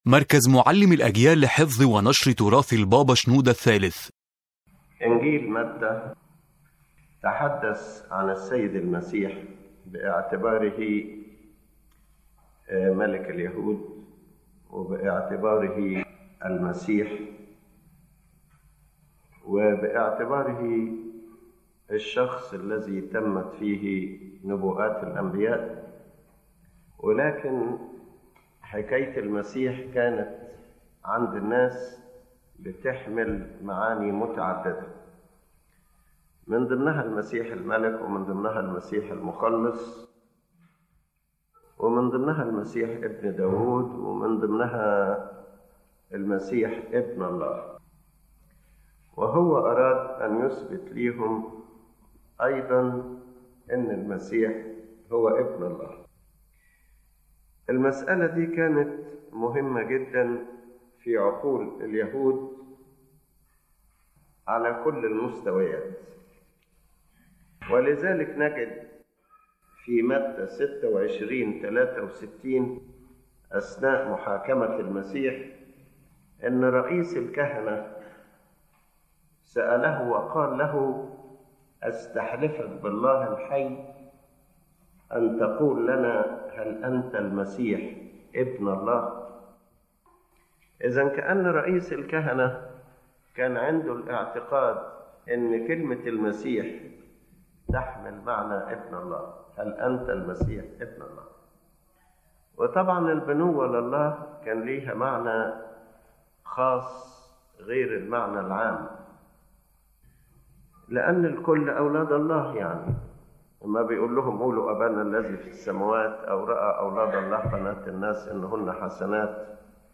The lecture explains that the Gospel of Matthew focuses primarily on revealing Christ as the Son of God, clarifying His relationship with the Father and His divine authority, while also presenting His human nature through the title “Son of Man.” The Gospel provides multiple testimonies confirming His divinity through miracles, teachings, and events.